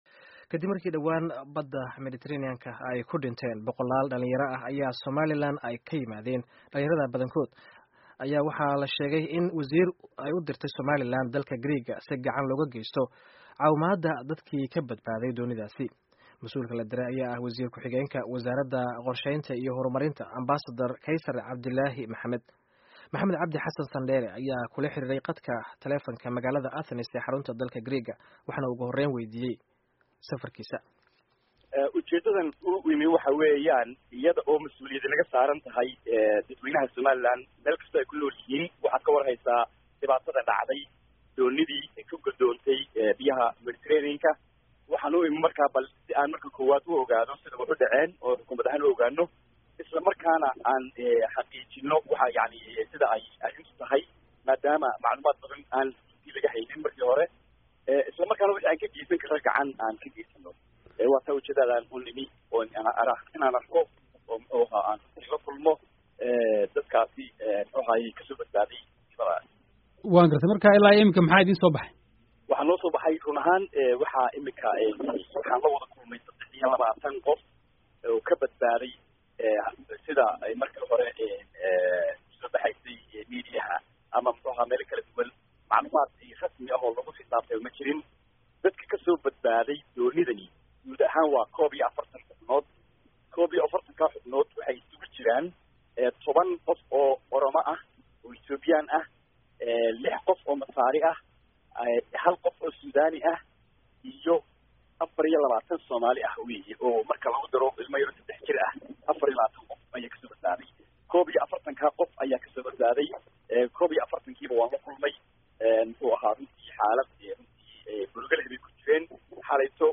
Waraysiga Wasiir Xigeenka Qorshaynta